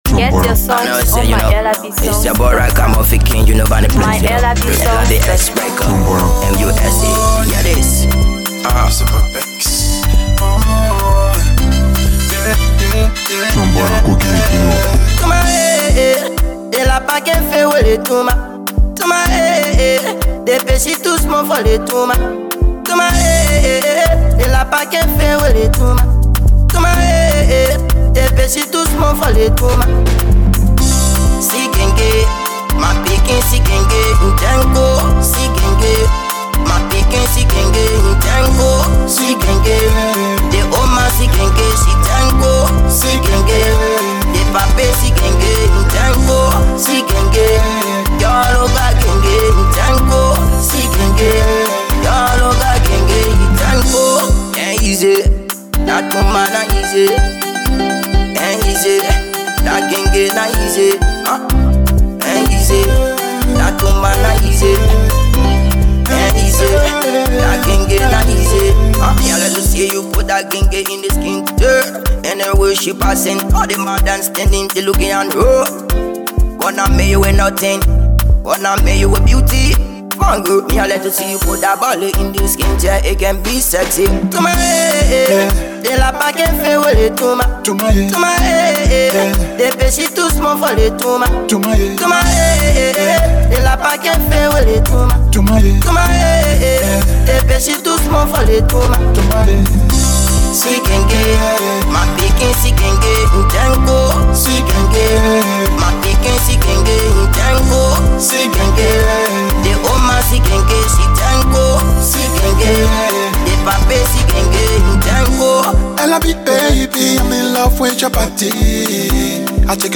Afro PopMusic
soulful vocals